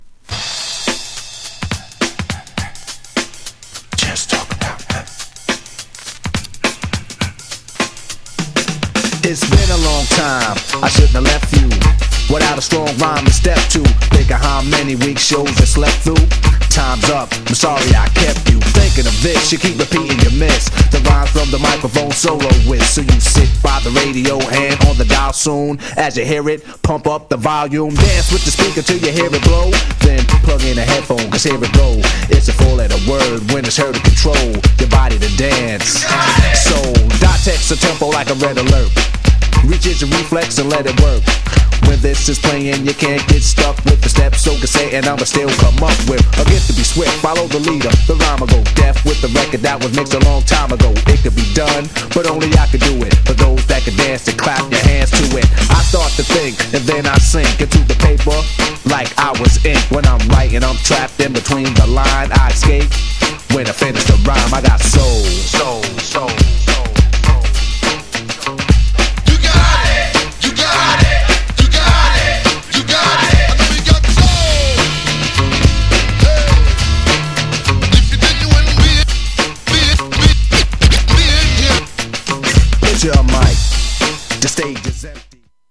GENRE Hip Hop
BPM 101〜105BPM
# 打ち付ける様なビートがアツイ # 間違いなくHIPHOP_CLASSIC